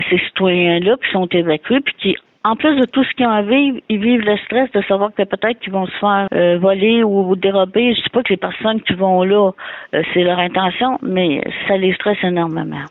En entrevue, la mairesse, Denise Gendron, ignorait toutefois pour combien de temps ils pourront recevoir cette aide.